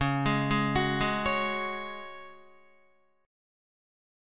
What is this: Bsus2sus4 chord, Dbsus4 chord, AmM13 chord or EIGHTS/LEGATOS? Dbsus4 chord